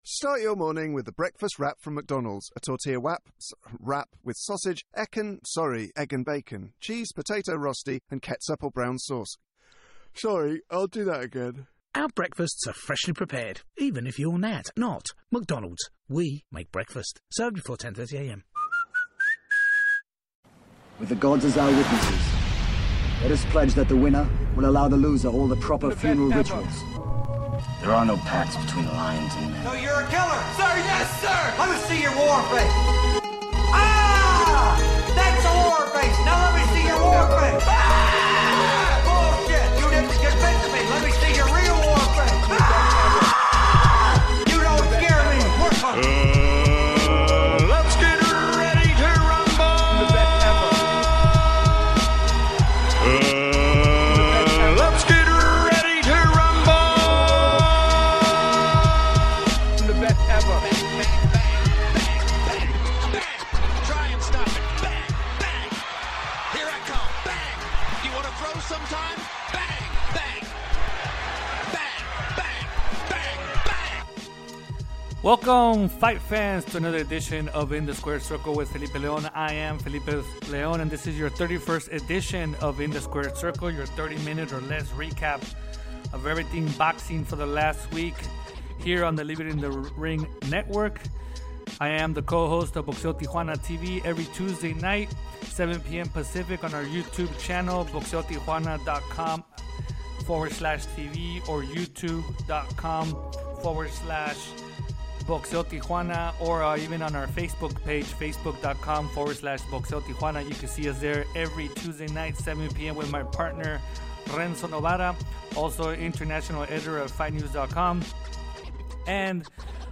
passes the latest news in the sport with a fast pace style of 30 minutes or less.